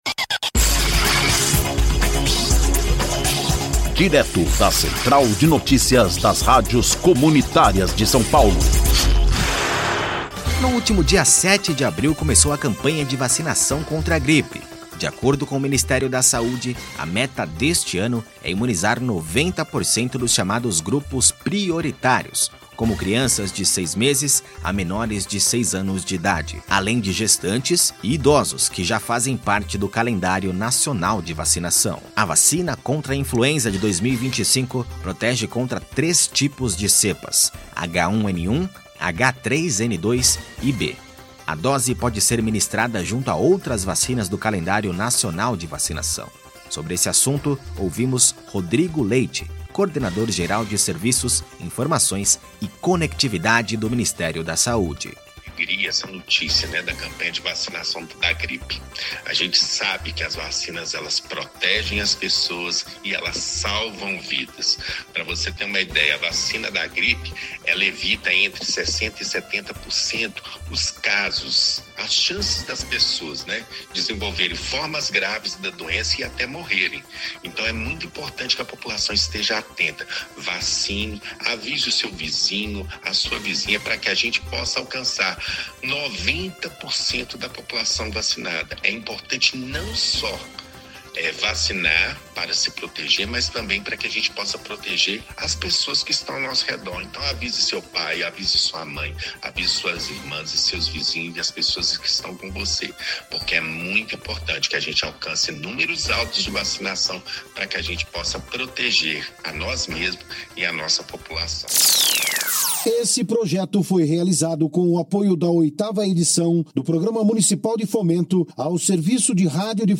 Ouça a notícia: Ministério da Saúde inicia campanha de vacinação contra gripe